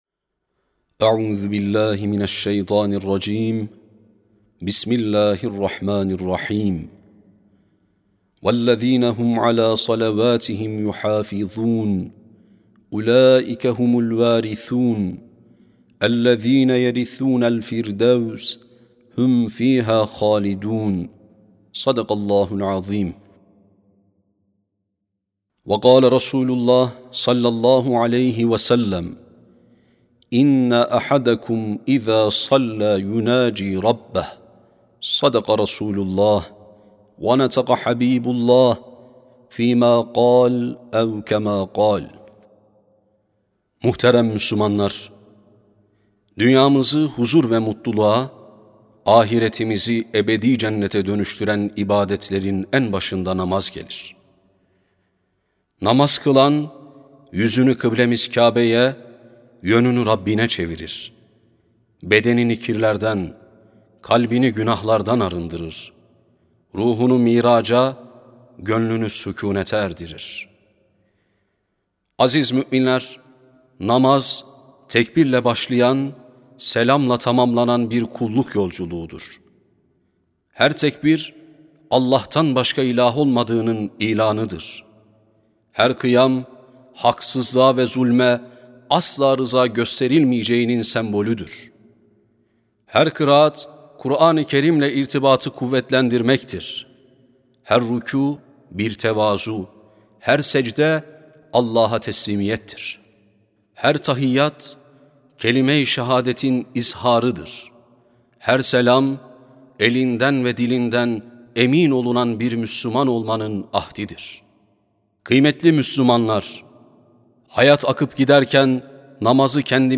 9 Ocak 2026 Tarihli Cuma Hutbesi
Sesli Hutbe (Namaz).mp3